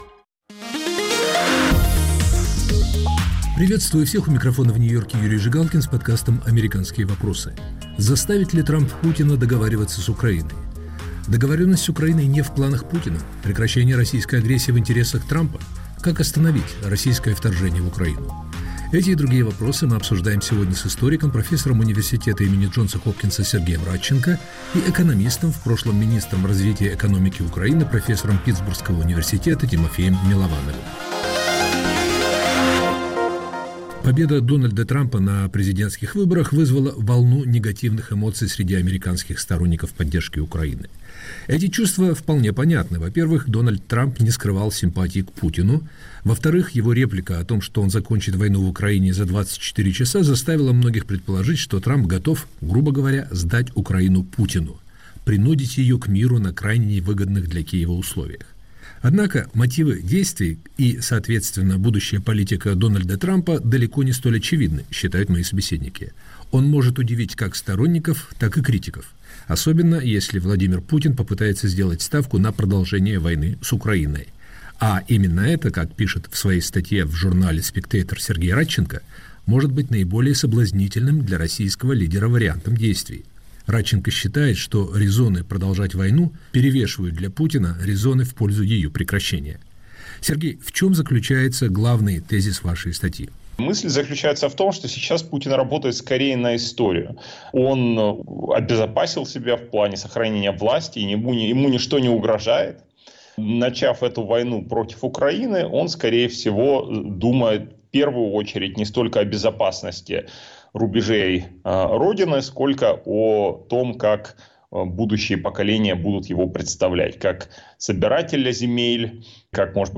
его гости - известные ученые и политологи - говорят об общественной дискуссии